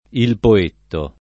[ il po % tto ]